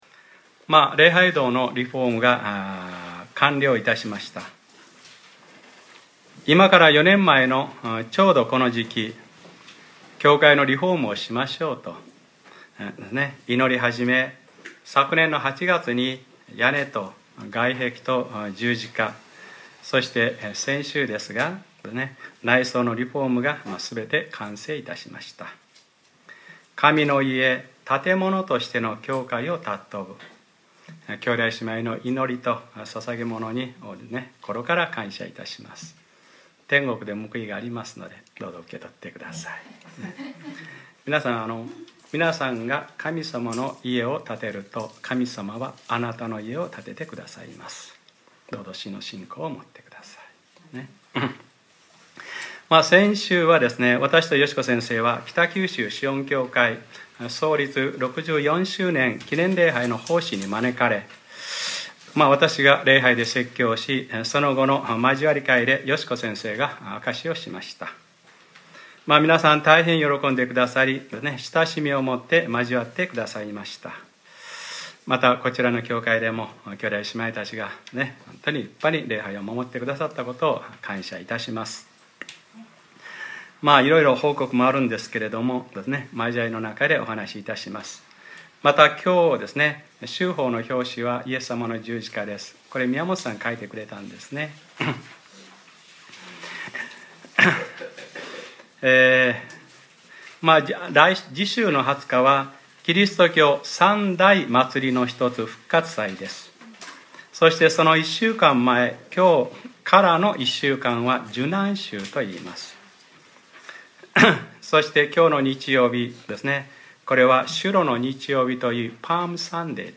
2014年 4月13日（日）礼拝説教『ルカ-３８：その人にあわれみをかけてやった人です』